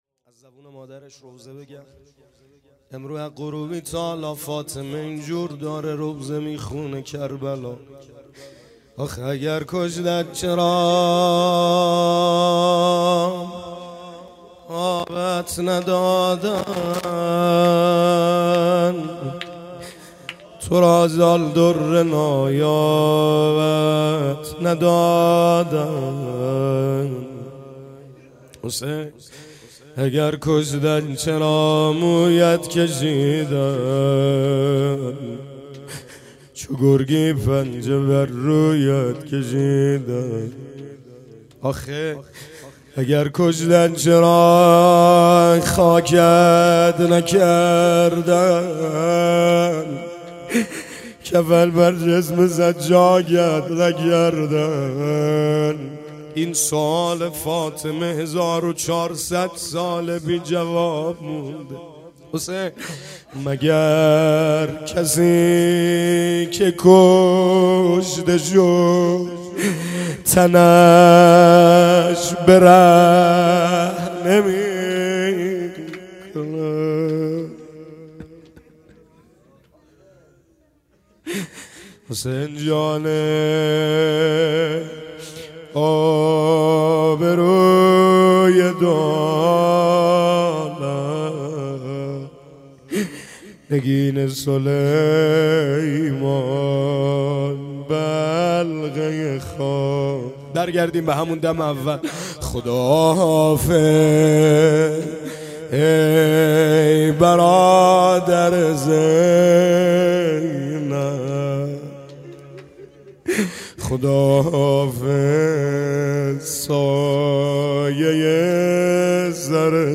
روضه ( اگر کشتند چرا آبت ندادند